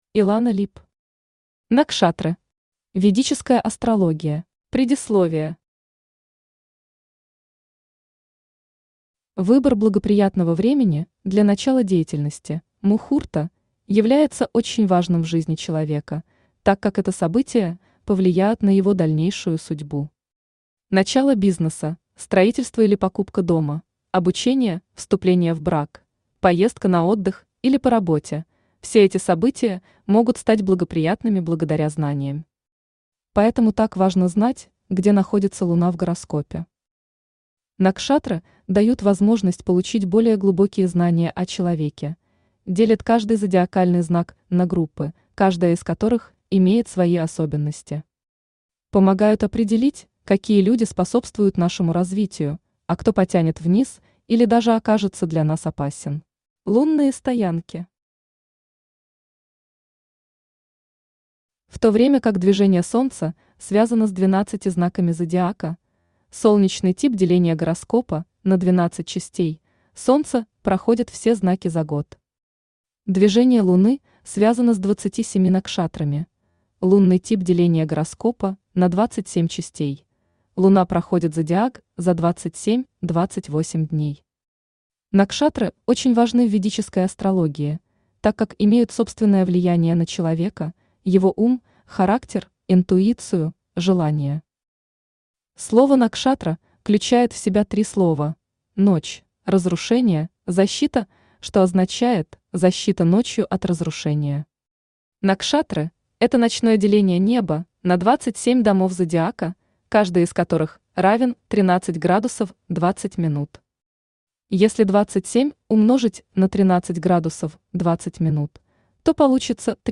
Аудиокнига Накшатры. Ведическая астрология | Библиотека аудиокниг
Ведическая астрология Автор Илана Либ Читает аудиокнигу Авточтец ЛитРес.